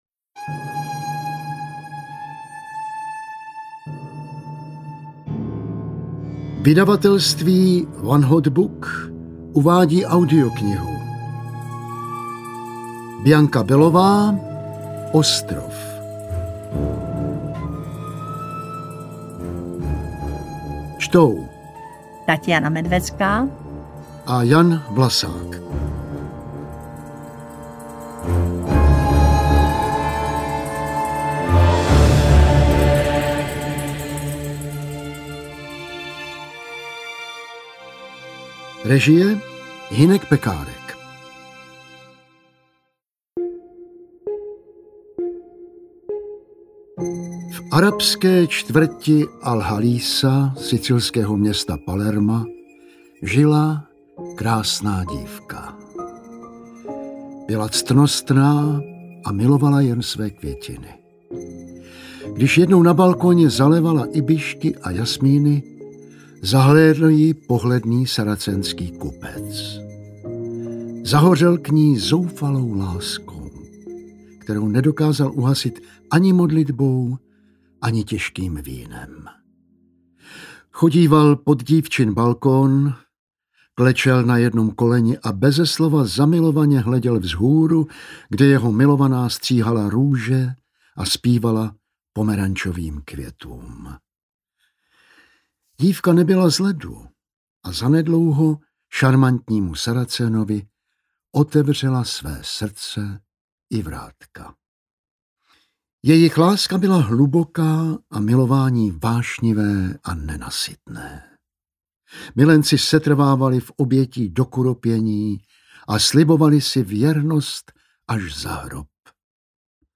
Interpreti:  Taťjána Medvecká, Jan Vlasák